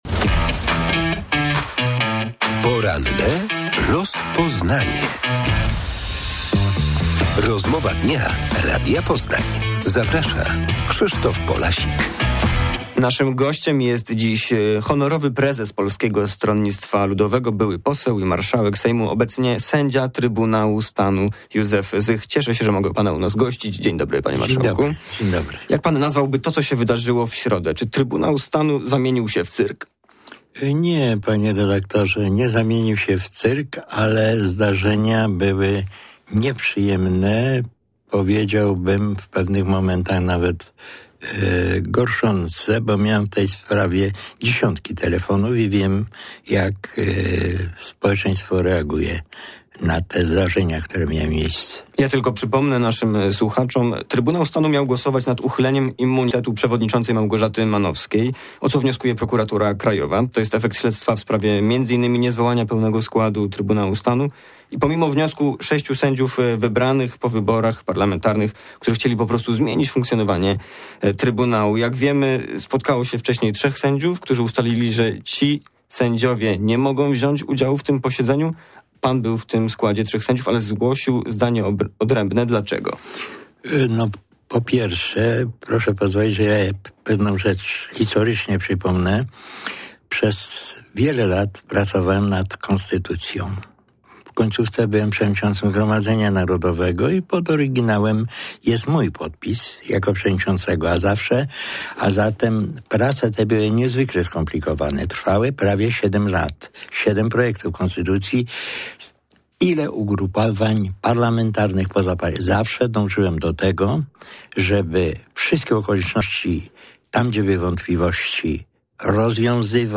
Sędzia Trybunału Stanu i honorowy prezes PSL Józef Zych komentuje zamieszanie podczas ostatniego posiedzenia Trybunału Stanu